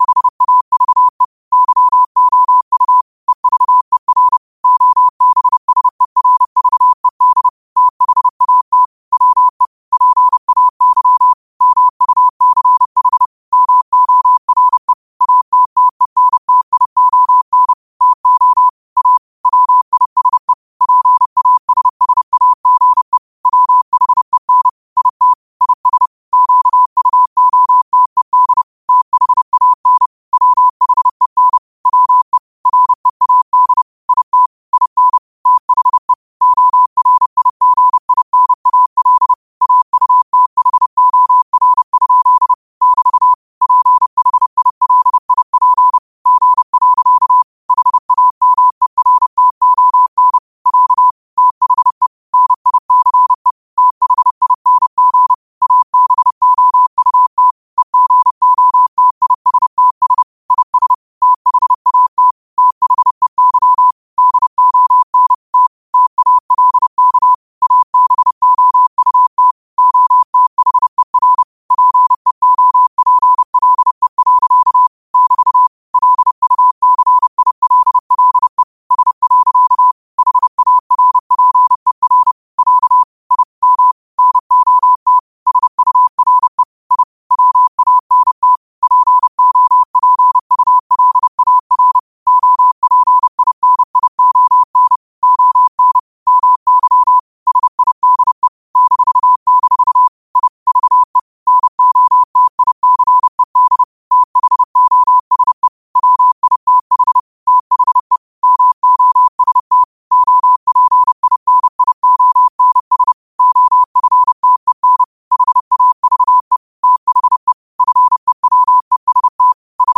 30 WPM morse code quotes for Wed, 13 Aug 2025 by QOTD at 30 WPM
Quotes for Wed, 13 Aug 2025 in Morse Code at 30 words per minute.